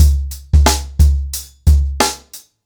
TheStakeHouse-90BPM.5.wav